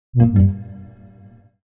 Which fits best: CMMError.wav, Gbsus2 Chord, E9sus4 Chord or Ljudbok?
CMMError.wav